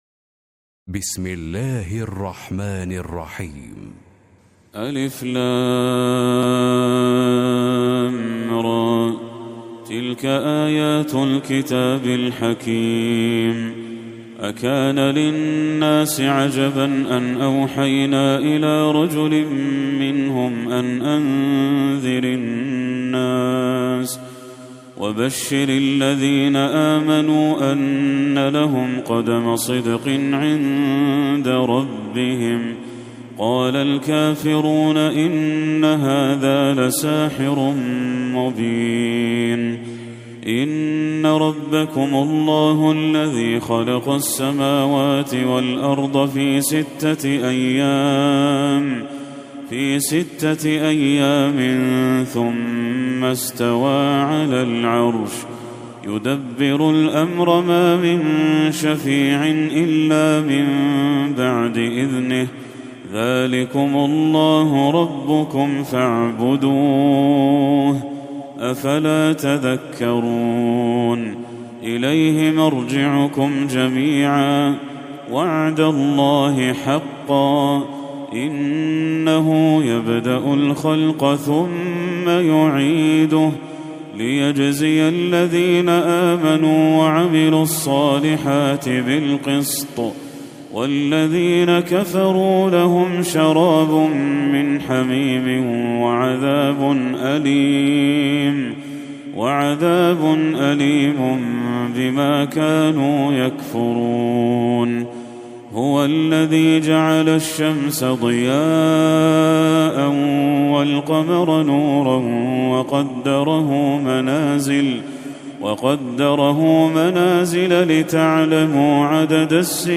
سورة يونس Surat Yunus > المصحف المرتل للشيخ بدر التركي > المصحف - تلاوات الحرمين